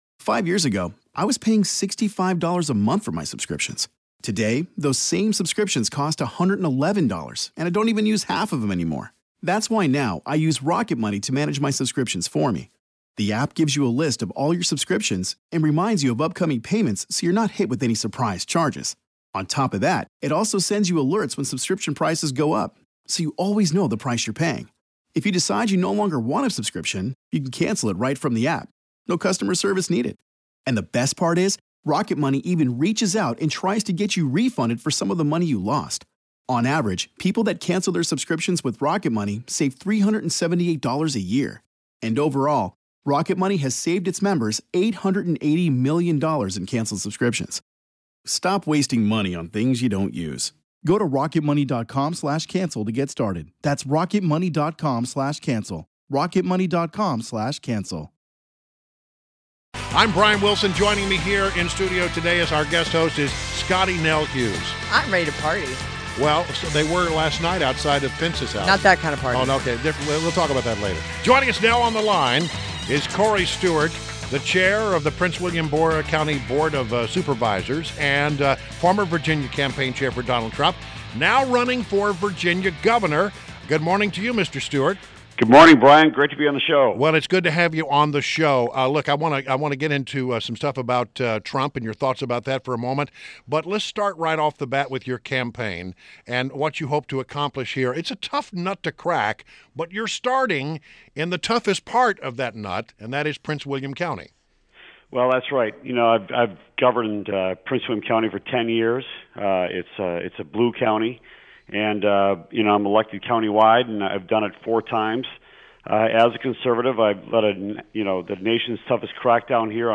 WMAL Interview - COREY STEWART - 01.19.17